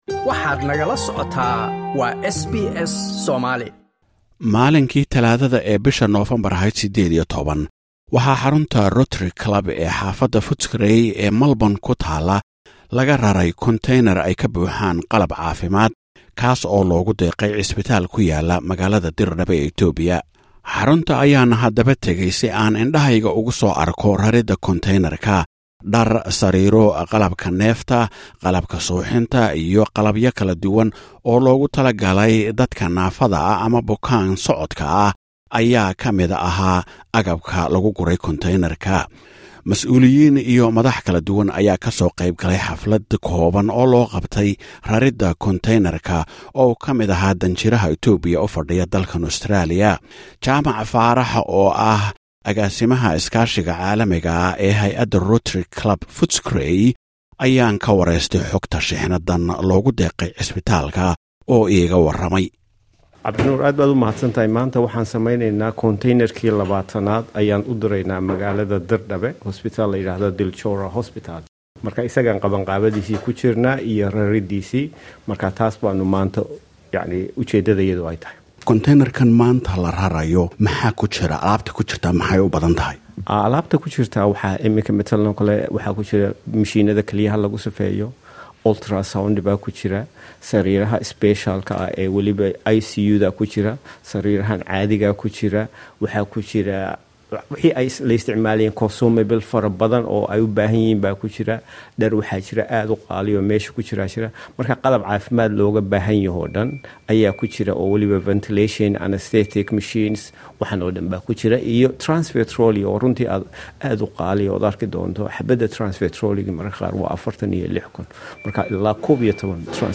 Waraysi